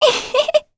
Talisha-Vox_Happy3.wav